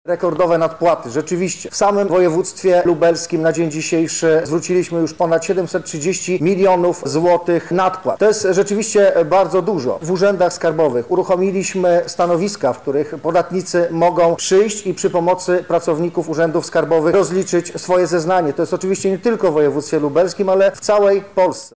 -mówi dr Artur Krukowski, Dyrektor Izby Administracji Skarbowej w Lublinie.